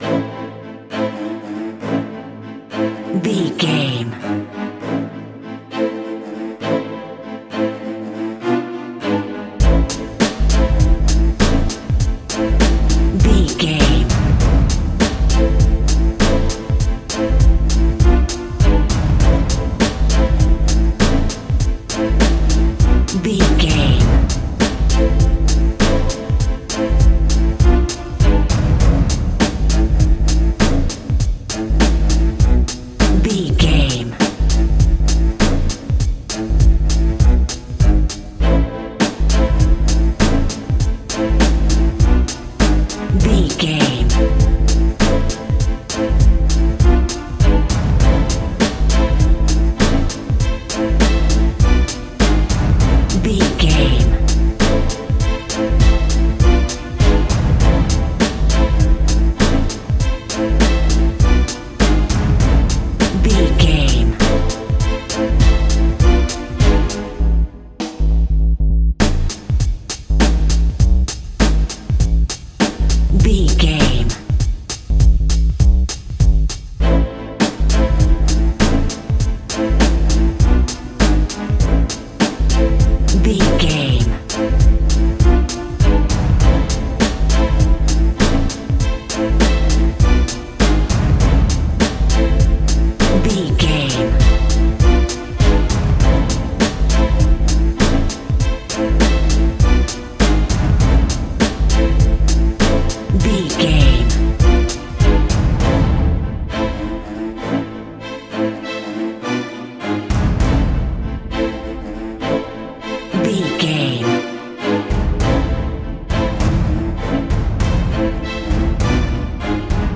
Aeolian/Minor
bass guitar
synthesiser
strings
drum machine
hip hop
Funk
neo soul
acid jazz
confident
energetic
bouncy
funky